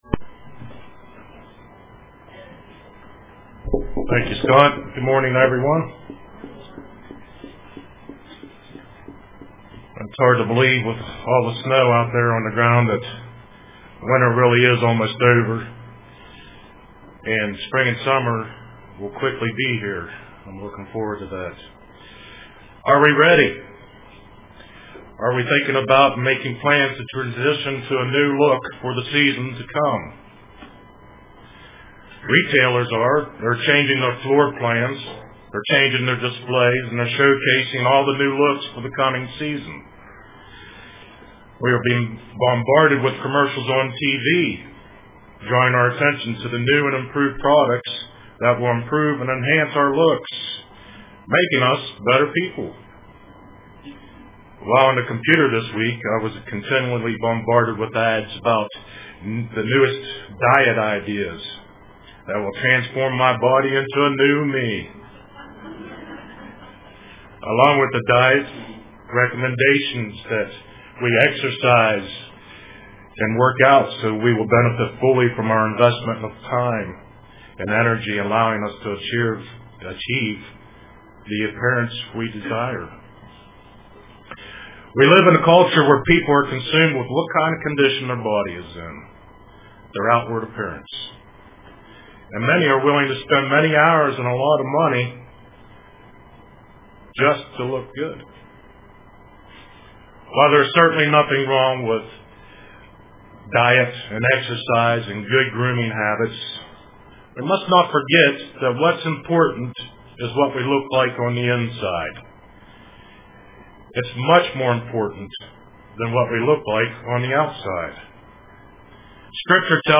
Print What does God see in Us UCG Sermon Studying the bible?